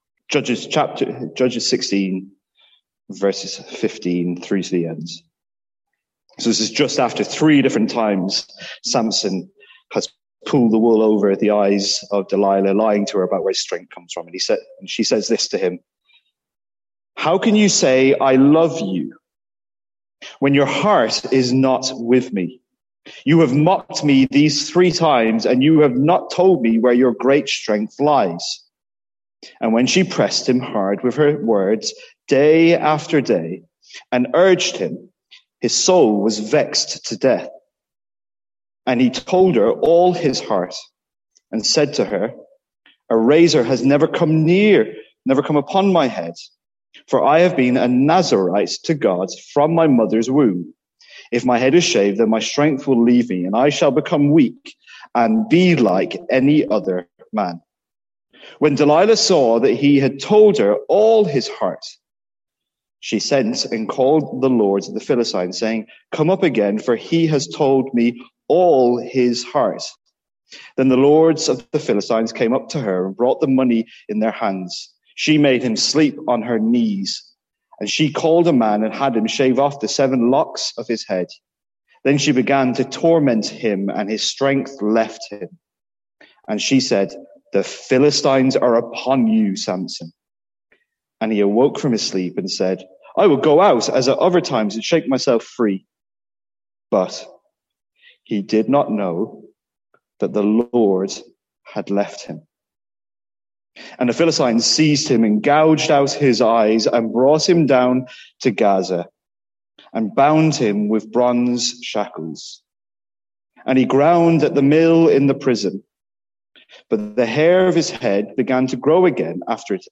Sermons | St Andrews Free Church
From our morning series in Judges.